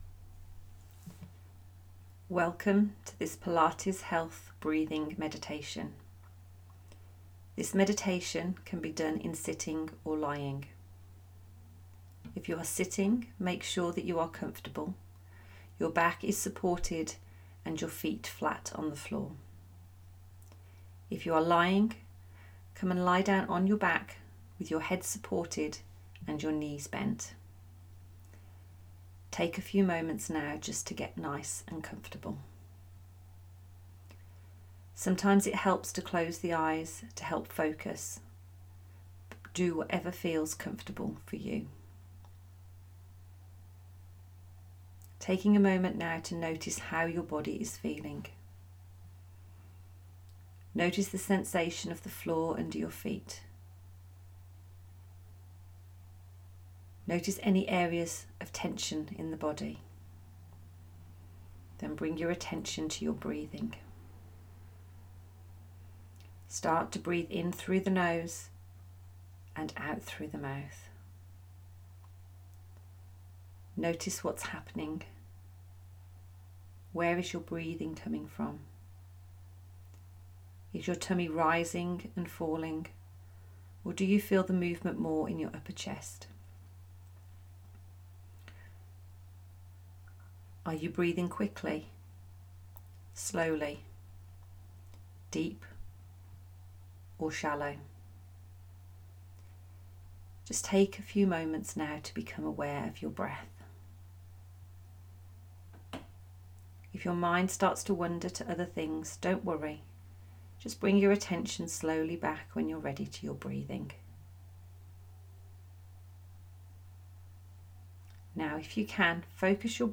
Breathing Meditation